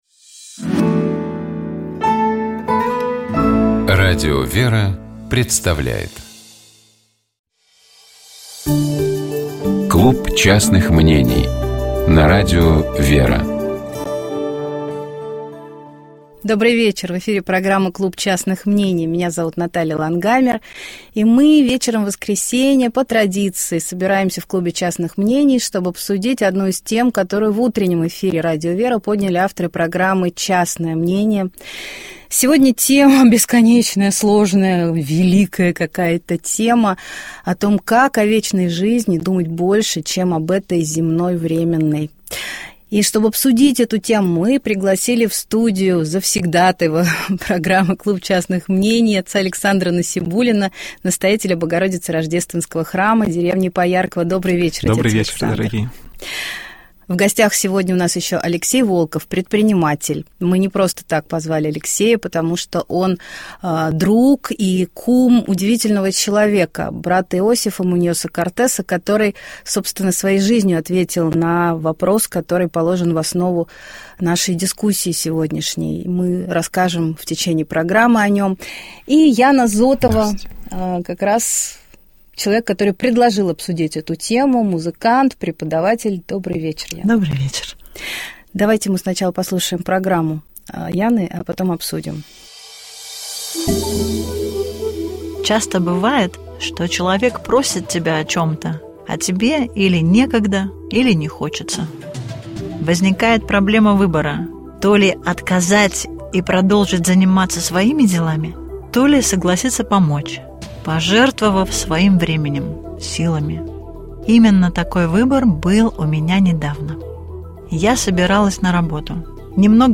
Общая теплая палитра программы «Еженедельный журнал» складывается из различных рубрик: эксперты комментируют яркие события, священники объясняют евангельские фрагменты, специалисты дают полезные советы, представители фондов рассказывают о своих подопечных, которым требуется поддержка.